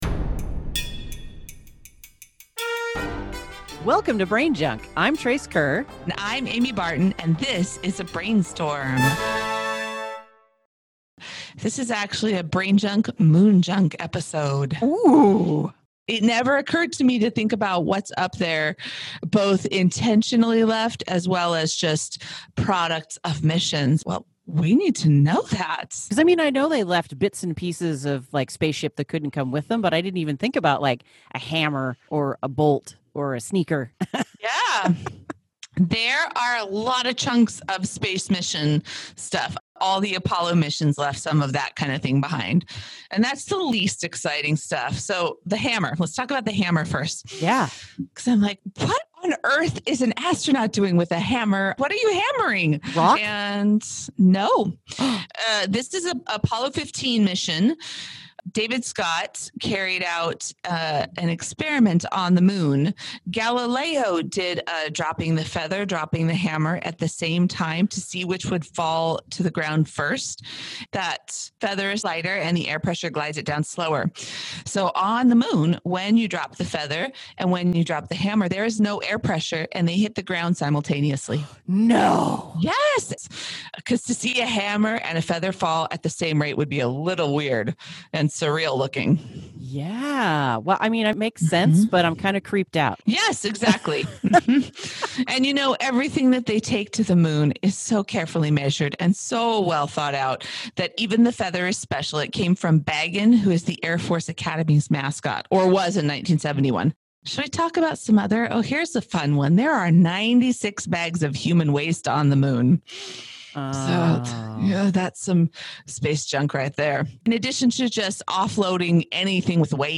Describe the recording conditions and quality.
A word on sound quality: We’re still recording remotely.